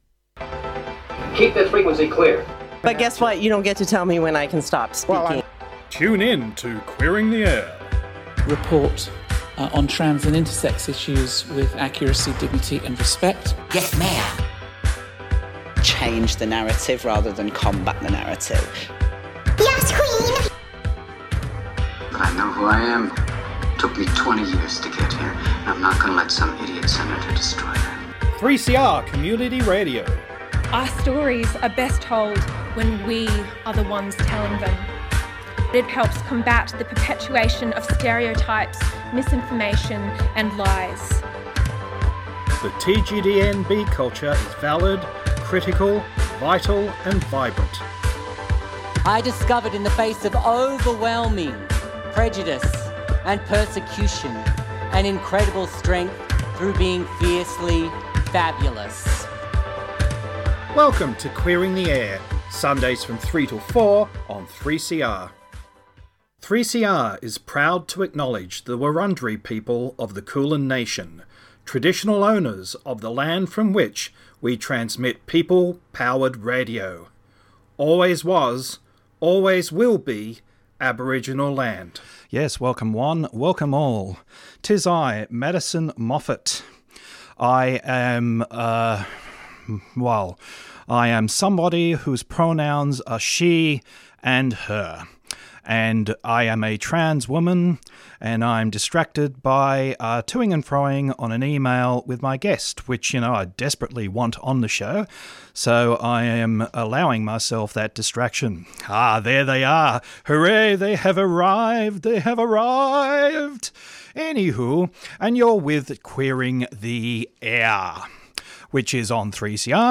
We also play recent interviews from the Bendigo St Homelessness and Housing Action.